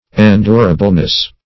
-- En*dur"a*ble*ness , n. [1913 Webster]